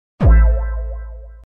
RIZZ SFX Meme Sound sound effects free download
RIZZ SFX - Meme Sound Effect